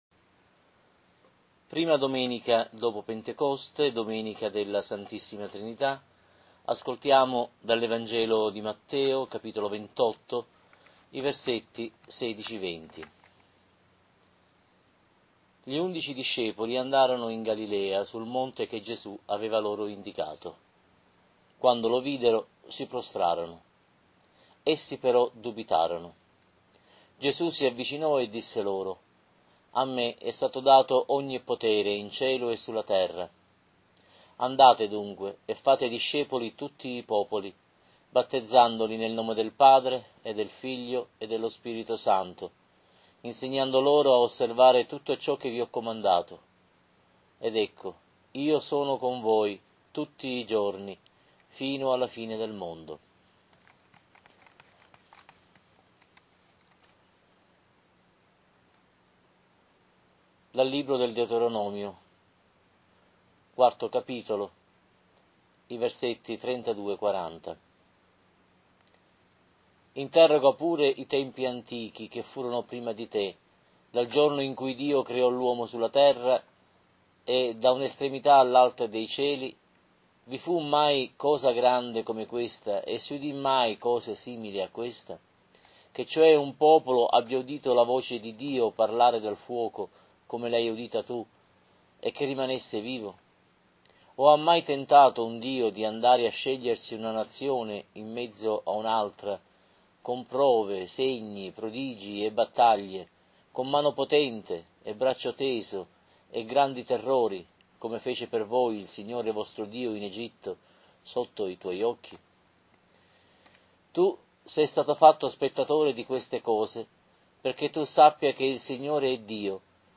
Lectio-Trinità-B-2021.mp3